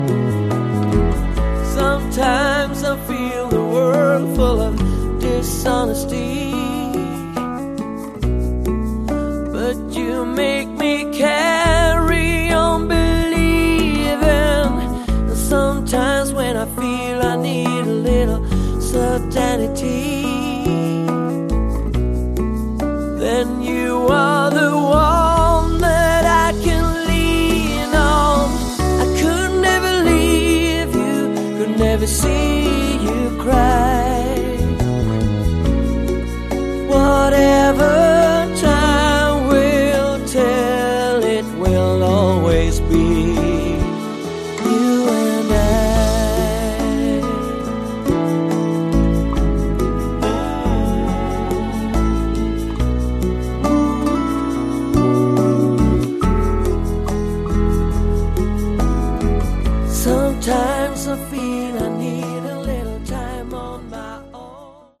Category: Melodic Hard Rock/AOR